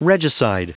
Prononciation du mot regicide en anglais (fichier audio)
Prononciation du mot : regicide